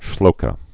(shlōkə)